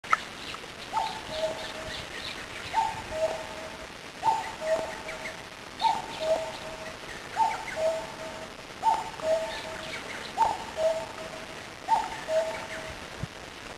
kakkou.mp3